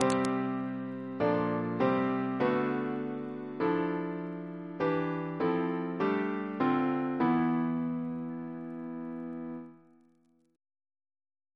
Single chant in C Composer: Richard Wayne Dirksen (1921-2003), Organist of Washington Cathedral Reference psalters: H1940: 787; H1982: S287